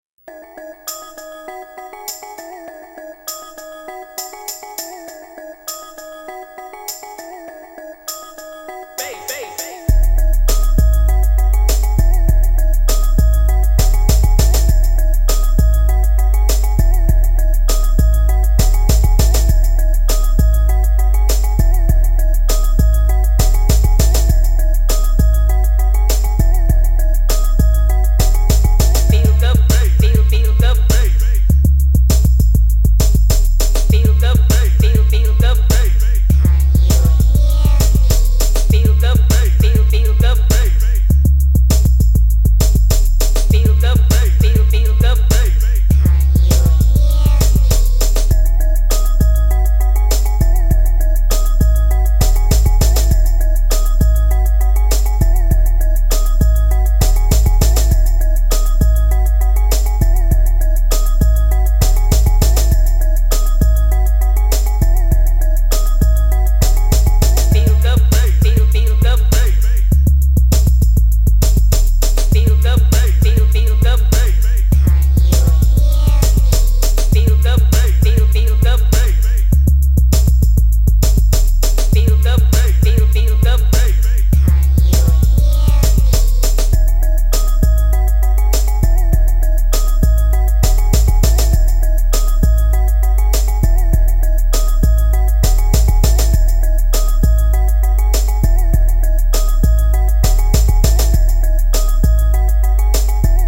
• اهنگ بیس دار